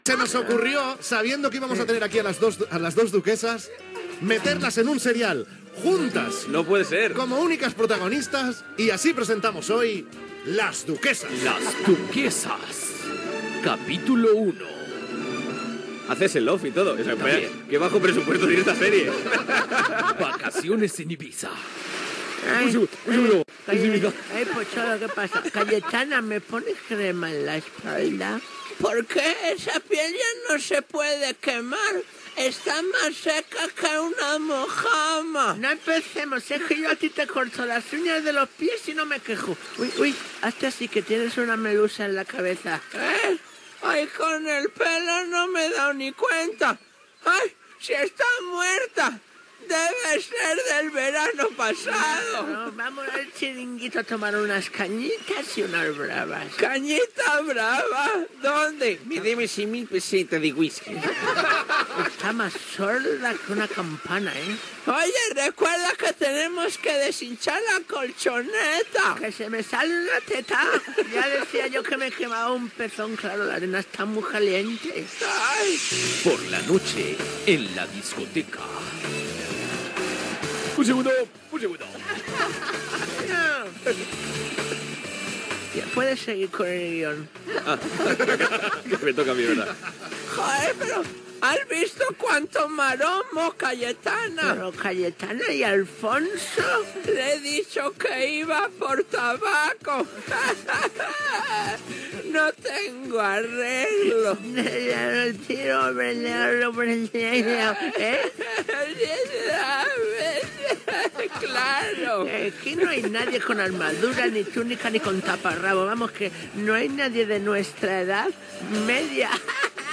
Carlos Latre i Raúl Pérez protagonizen "Las Duquesas", imitant a la Duquesa d'Alba, Cayetana Fitz-James Stuart
Entreteniment